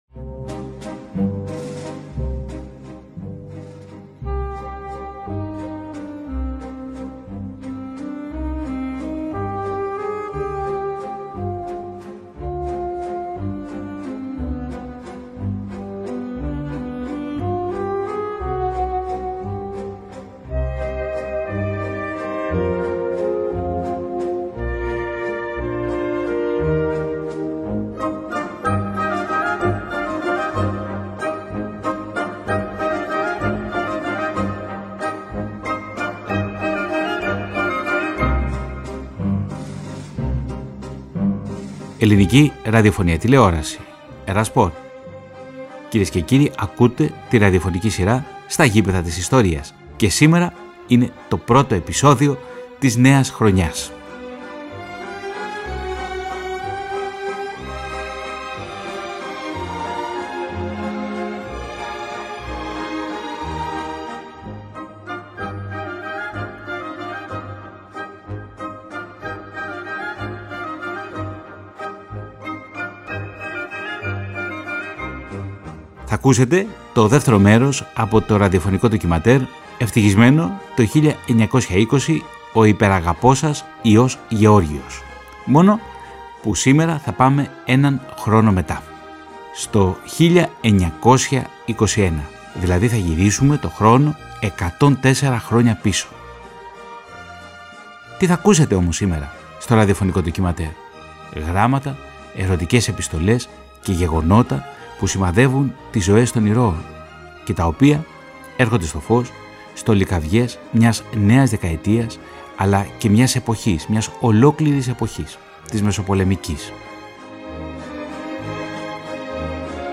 Η ραδιοφωνική σειρά της ΕΡΑ ΣΠΟΡ “Στα γήπεδα της Ιστορίας” παρουσιάζει σε συνεργασία με το Κέντρο Μικρασιατικών Σπουδών ένα συναρπαστικό ραδιοφωνικό ντοκιμαντέρ φέρνοντας για πρώτη φορά στο φως την επιστολογραφία στρατιωτών του Μικρασιατικού Μετώπου, εστιάζοντας όμως σε όλα όσα συνέβησαν στο πολεμικό μέτωπο κατά τη διάρκεια του 1920 μέσα από τα μάτια των απλών οπλιτών.